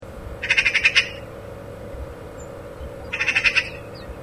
harakas.mp3